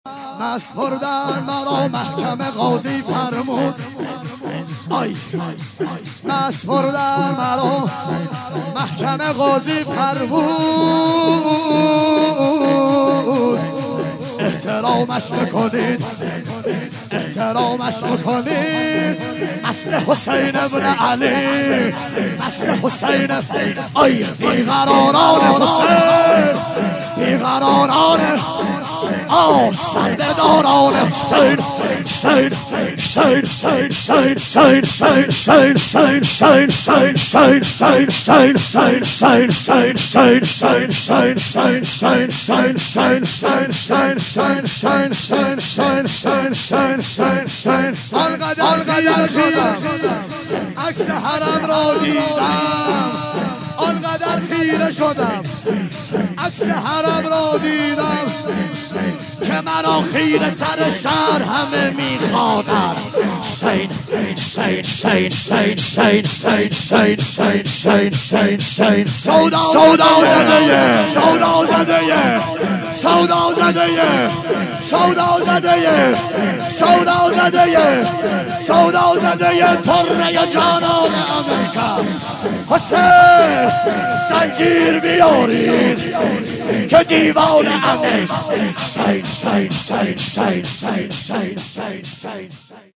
8- رجز